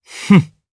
DarkKasel-Vox_Happy1_jp.wav